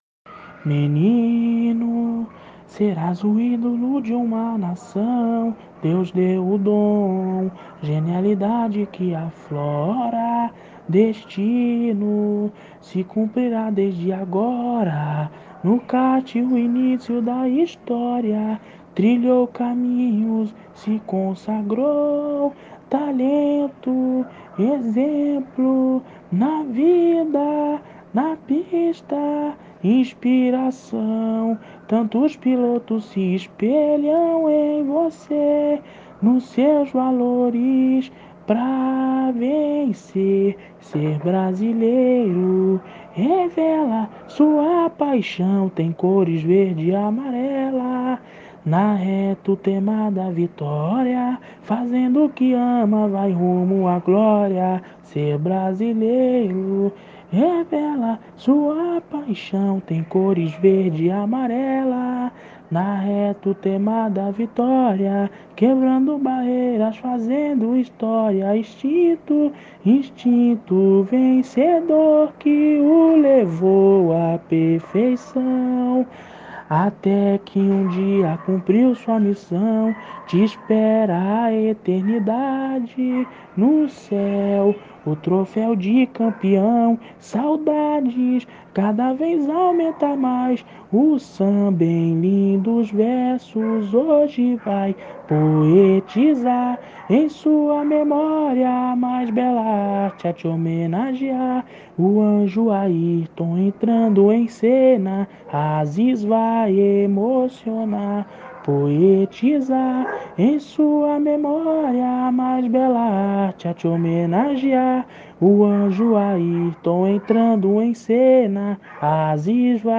Samba  07 - SAMBA CAMPEÃO